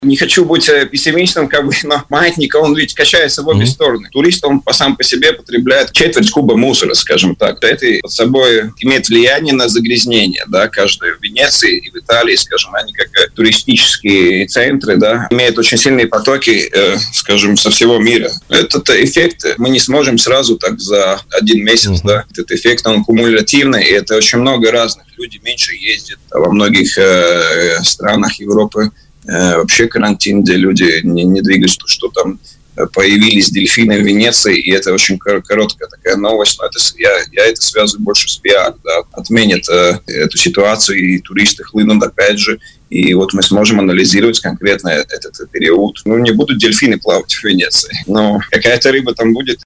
в эфире радио Baltkom